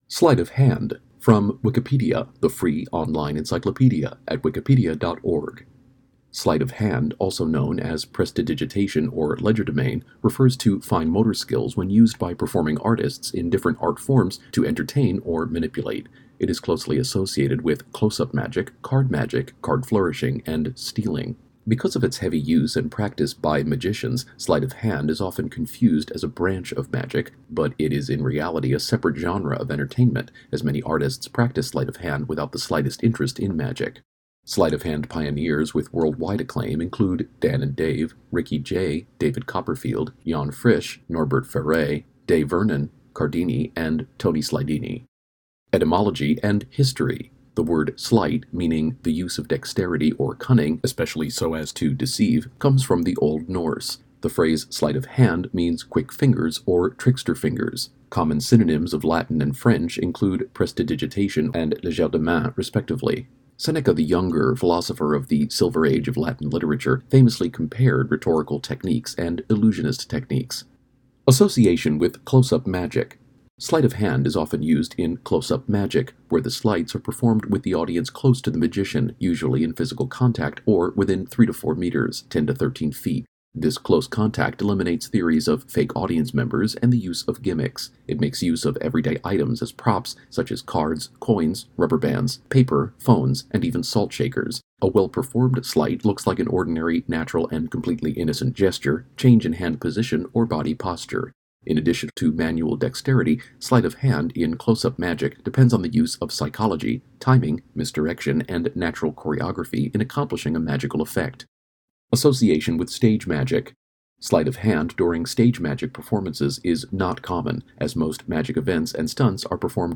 Captions English Spoken article: "Sleight of hand"